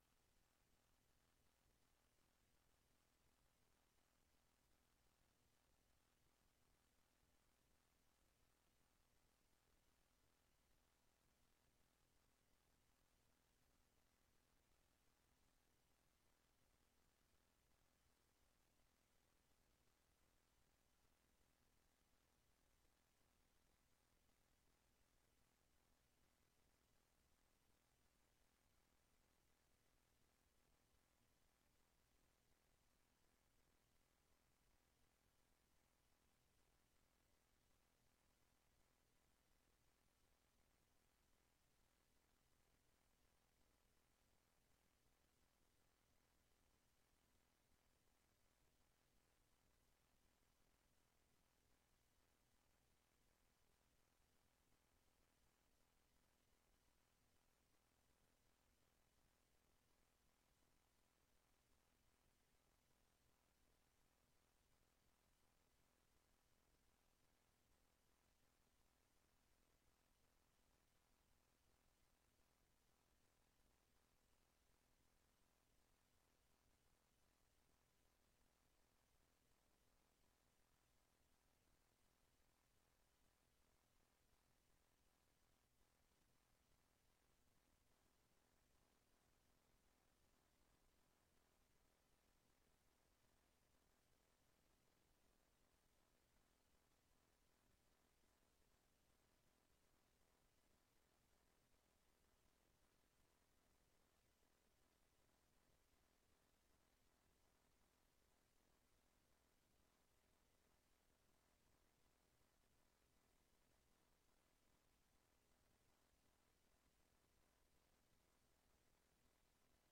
Raadsinformatieavond (SESSIE B)